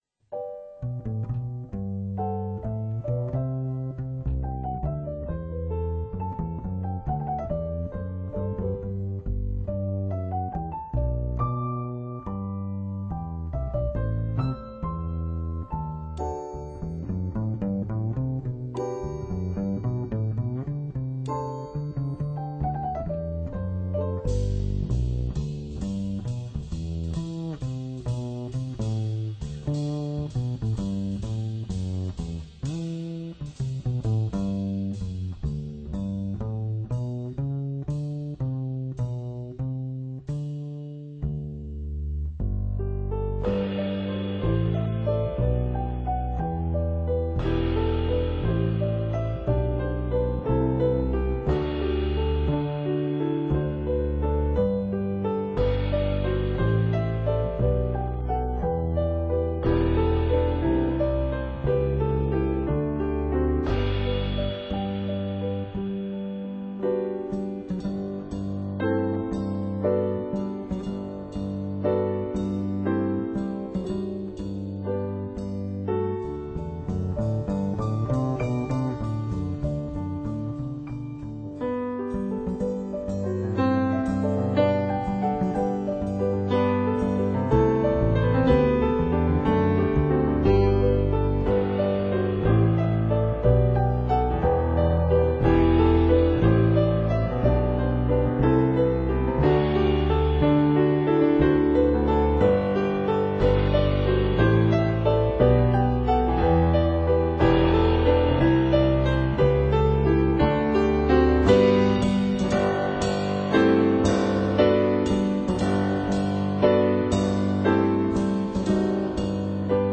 钢琴
鼓
贝斯